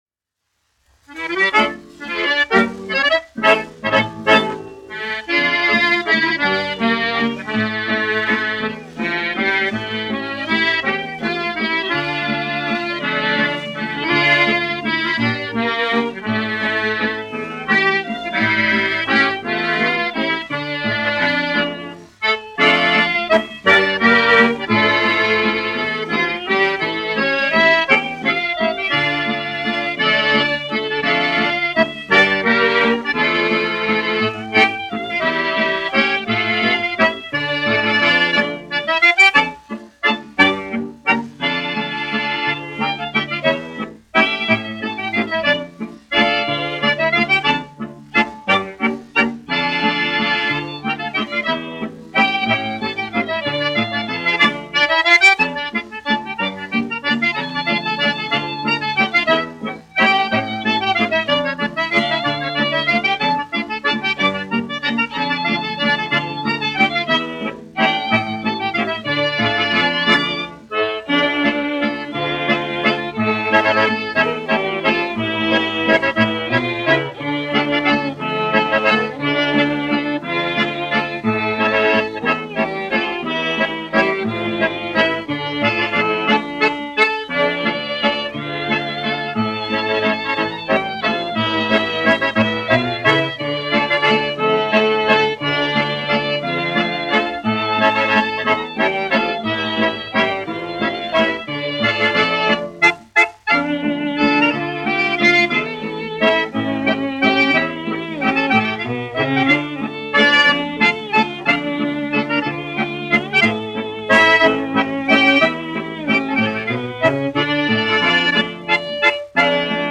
1 skpl. : analogs, 78 apgr/min, mono ; 25 cm
Populārā instrumentālā mūzika